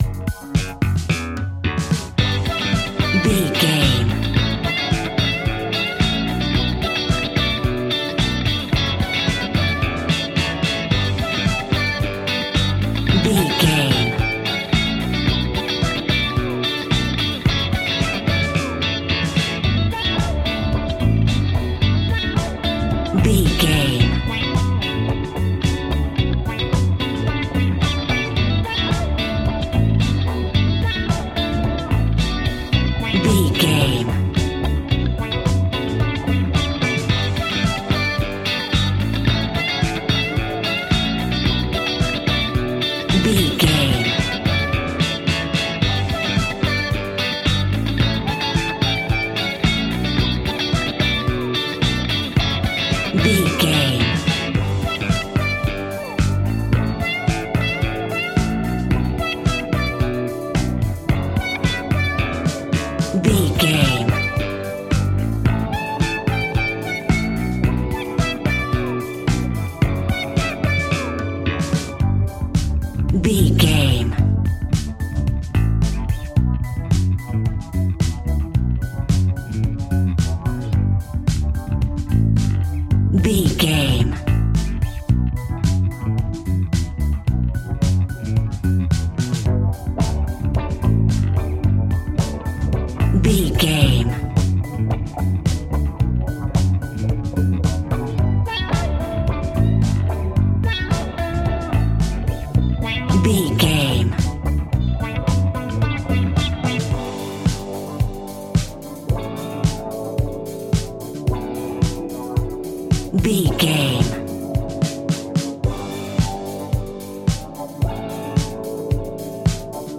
Ionian/Major
D♭
house
electro dance
synths
techno
trance
instrumentals